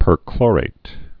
(pər-klôrāt)